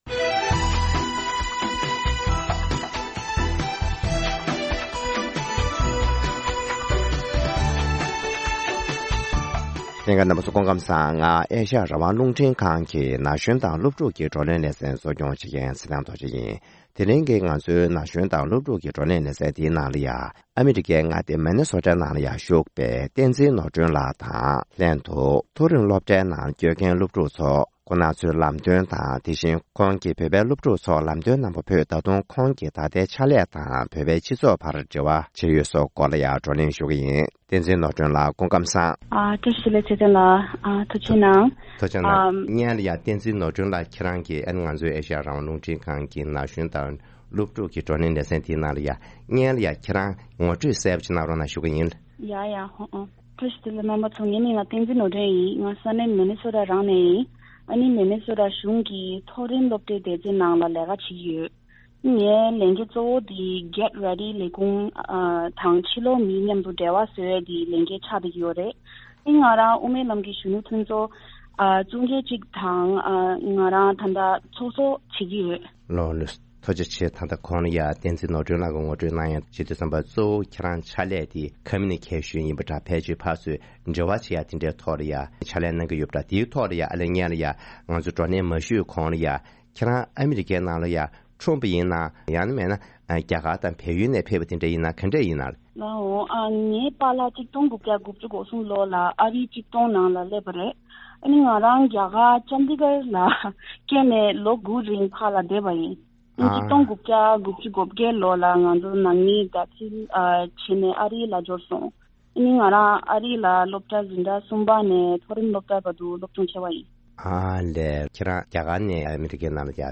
༄༅། །ཐེངས་འདིའི་ན་གཞོན་དང་སློབ་ཕྲུག་གི་བགྲོ་གླེང་ལེ་ཚན་འདིའི་ནང་དུ།